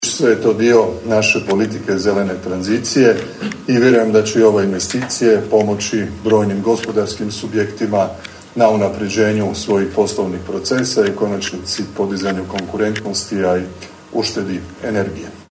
Premijer je uputio čestitke za druge projekte iz modernizacijskog fonda u nadležnosti Ministarstva zaštite okoliša i zelene tranzicije